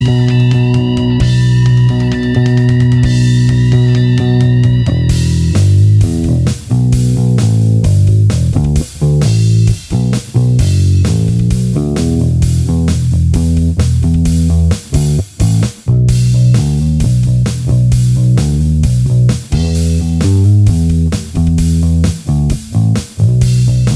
Minus Lead Guitar Rock 4:26 Buy £1.50